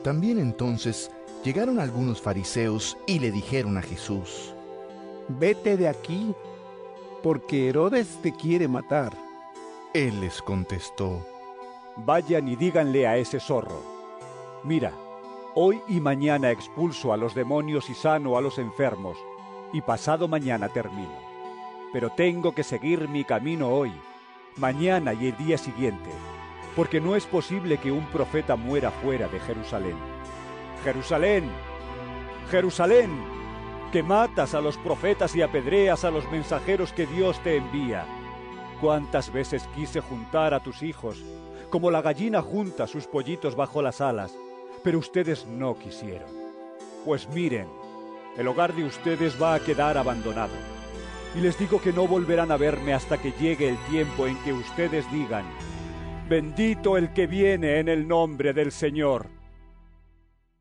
Lc 13 31-35 EVANGELIO EN AUDIO